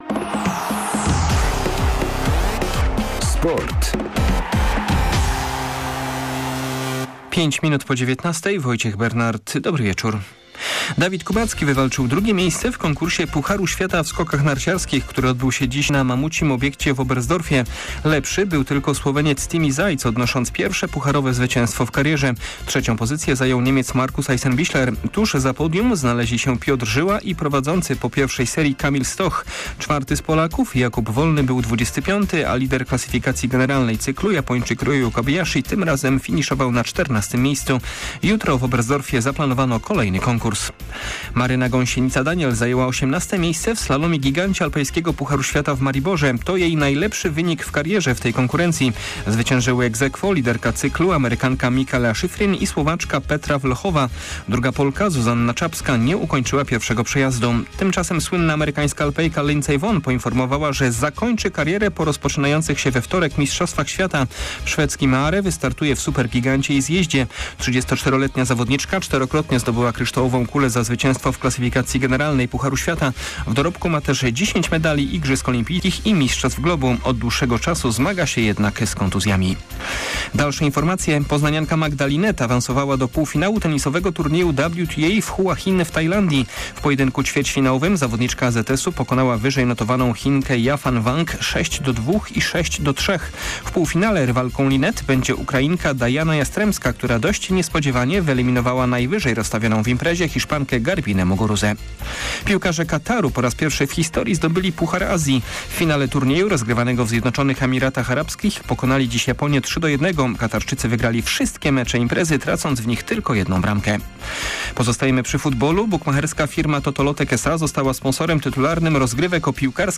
01.02. SERWIS SPORTOWY GODZ. 19:05